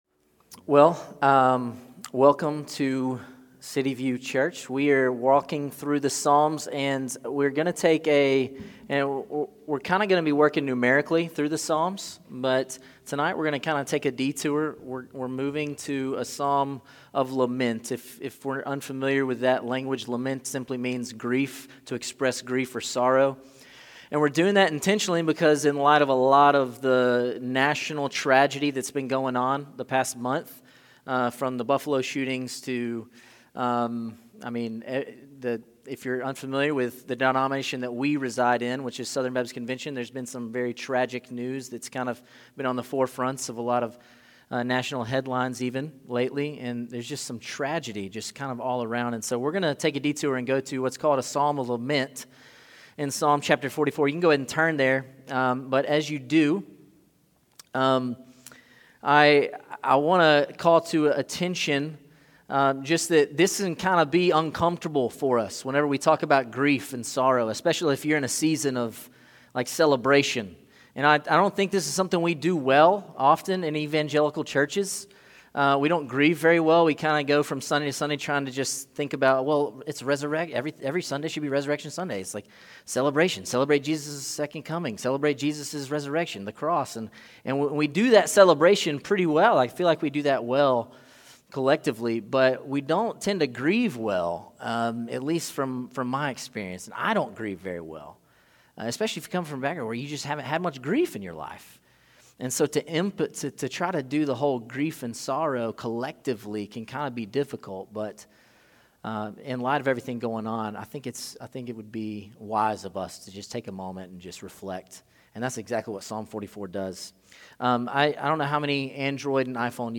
City View Church - Sermons "Wake Up, Lord!"